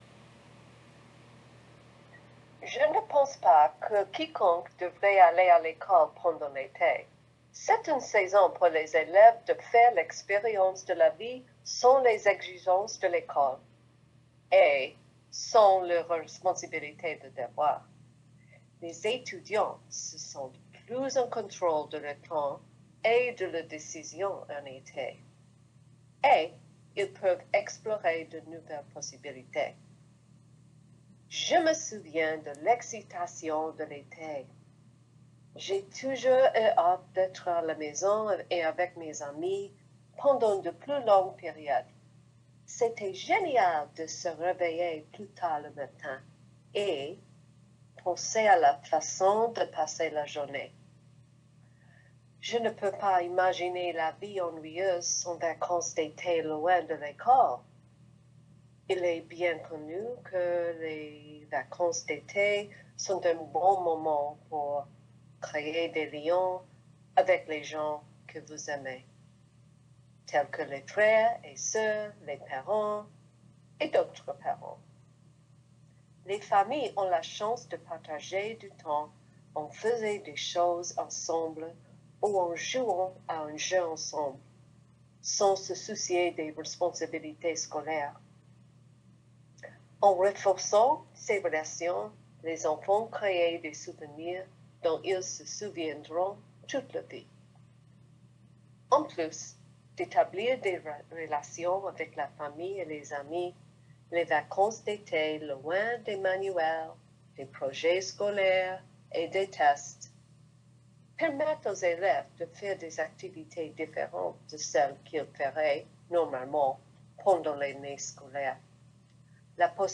Presentational Speaking: French
[Note: In the transcript below, ellipses indicate that the speaker paused.]